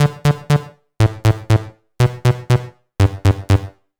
TSNRG2 Lead 011.wav